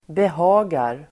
Uttal: [beh'a:gar]